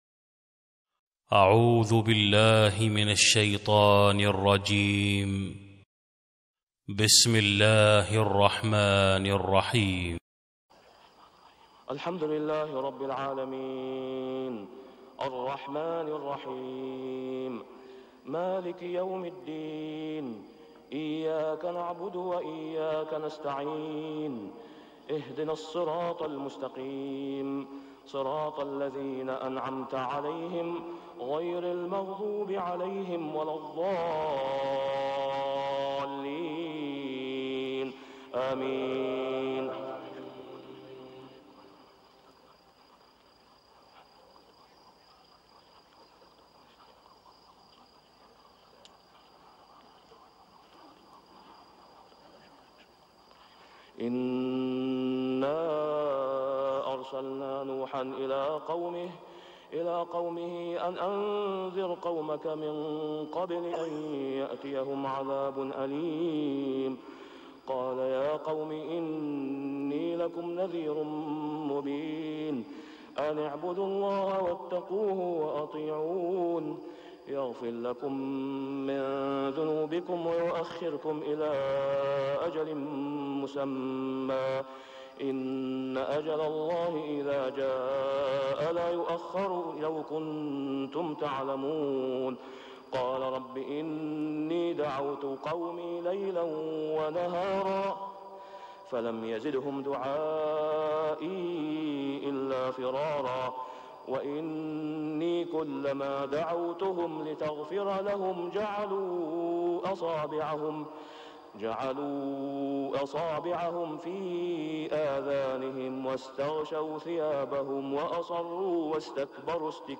صلاة الفجر ذو القعدة 1421هـ فواتح سورة نوح 1-28 > 1421 🕋 > الفروض - تلاوات الحرمين